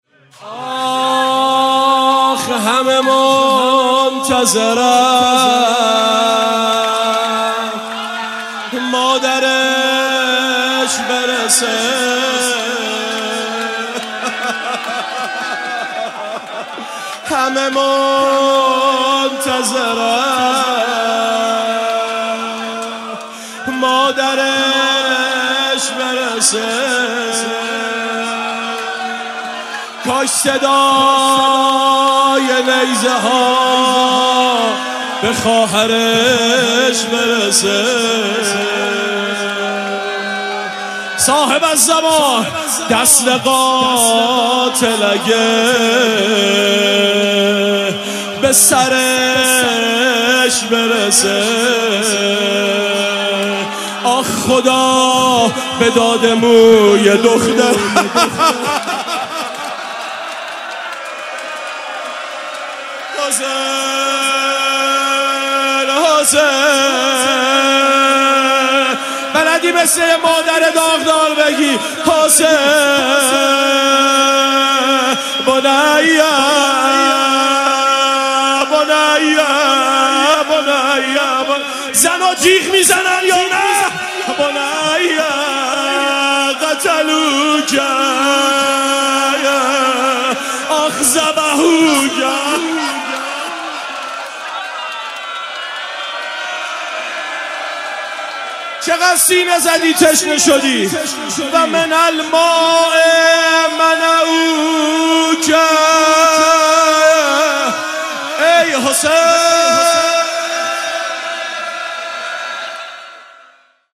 شور همه منتظرن مادرش برسه
روز عاشورا